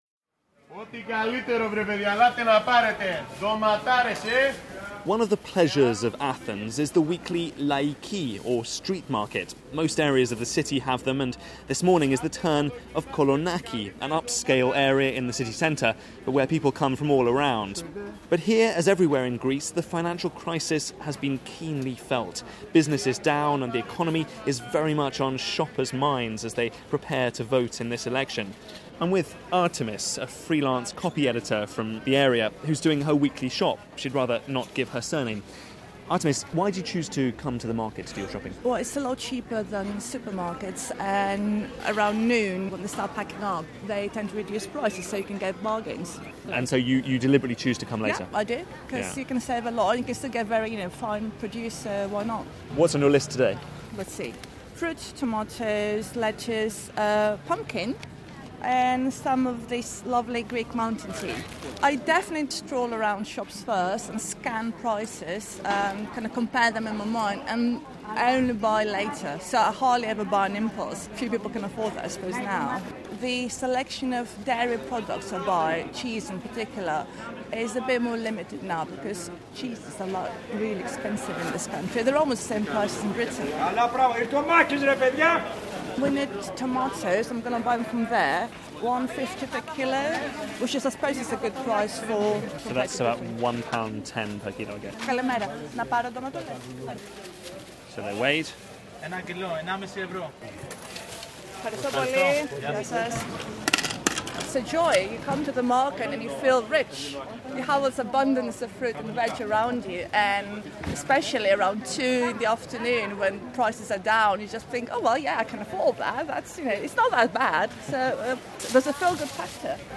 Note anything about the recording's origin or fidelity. Piece for 'Broadcasting House', BBC Radio 4, on how four years of austerity have hit the cost of living in Greece. We report from a street market in Athens